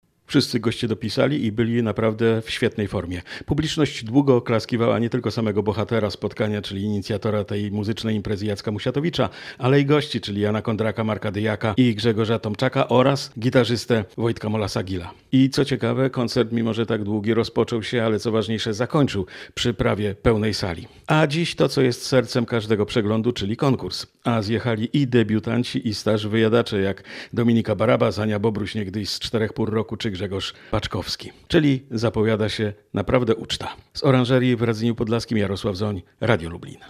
Właśnie rozpoczynają się przesłuchania konkursowe. Na miejscu jest nasz reporter.
Oranzeria-relacja.mp3